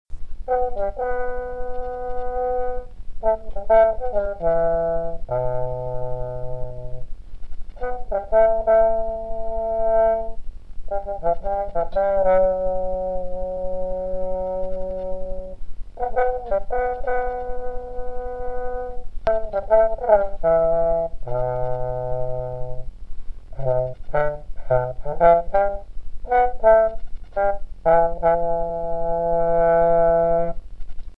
posaunt ein Lied für Weihnachtsmuffel